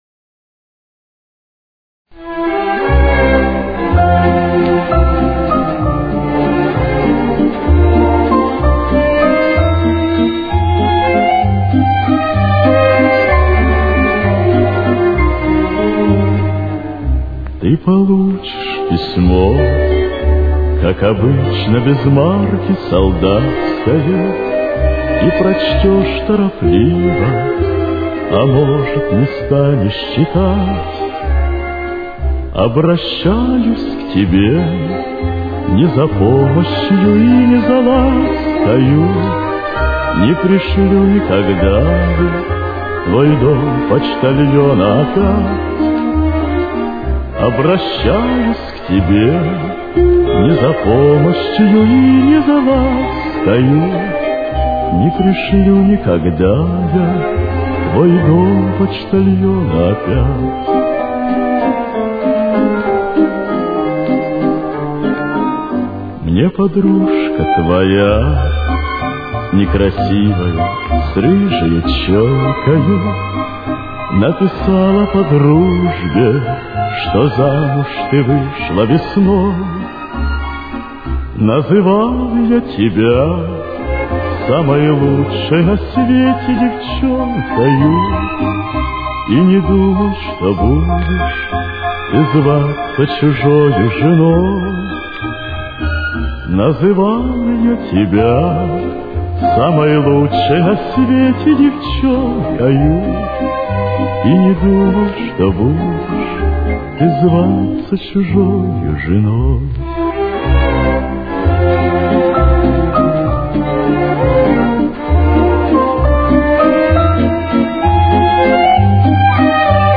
Темп: 195.